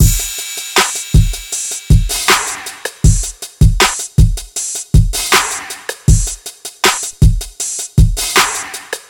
• 79 Bpm Modern Drum Groove D# Key.wav
Free drum beat - kick tuned to the D# note. Loudest frequency: 2459Hz
79-bpm-modern-drum-groove-d-sharp-key-AX8.wav